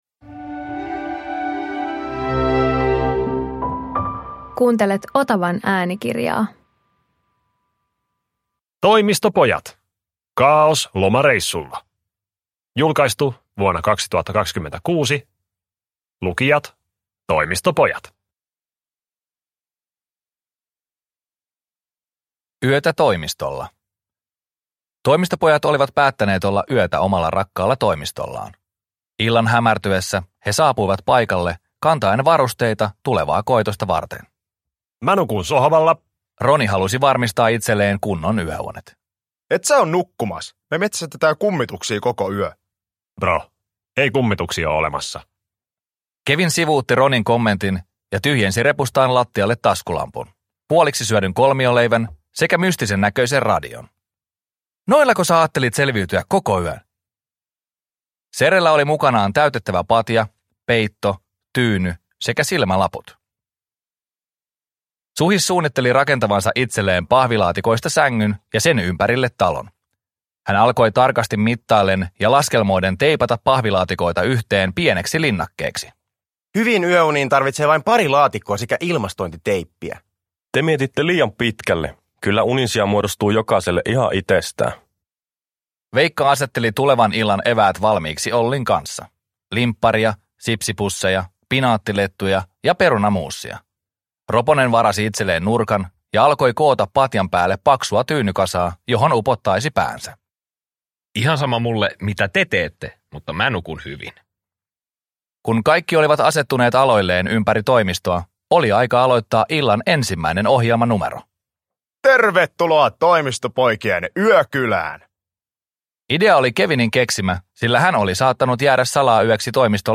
Toimistopojat - Kaaos lomareissulla – Ljudbok